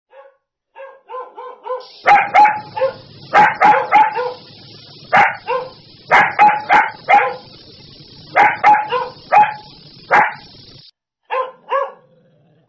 Ladridos de perros 1, efectos de sonido, descargar en mp3 gratis
Categoría: Efectos de Sonido
Tipo: sound_effect
Ladridos de perros.mp3